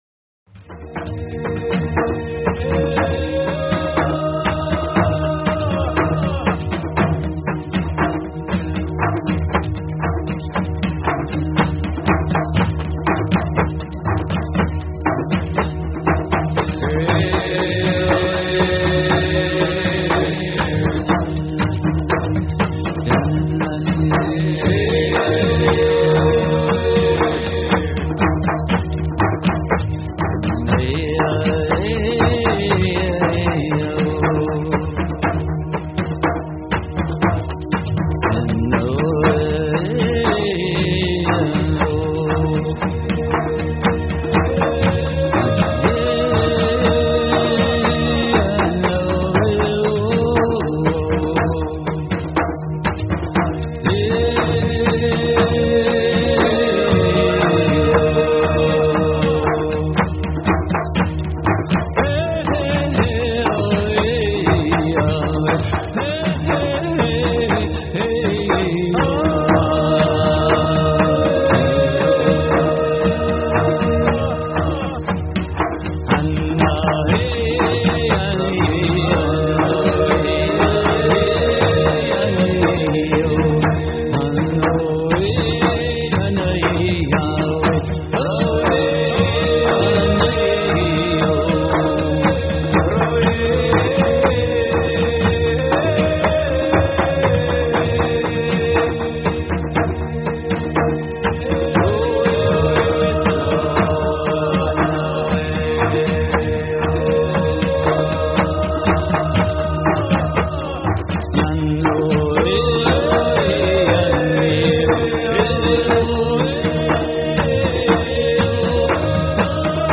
Talk Show Episode, Audio Podcast, DreamPath and Courtesy of BBS Radio on , show guests , about , categorized as
From the sublime to the ridiculous and everything in between. Comedy, music, and a continuous weaving of interviews and story telling (DreamPath style) covering numerous alternative thought topics.